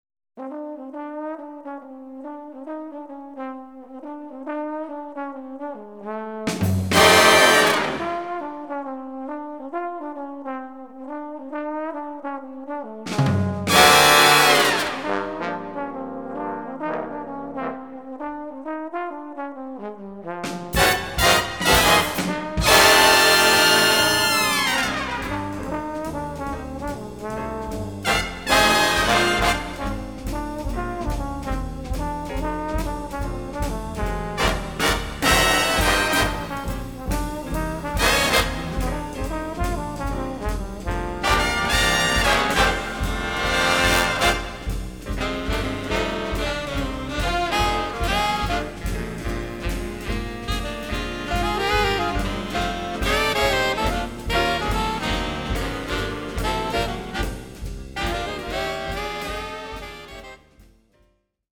Die Beispiele sind sorgfältig auf gleiche Lautheit eingestellt, damit Sie auch wirklich die Klangqualitäten und nicht die Lautheit beurteilen.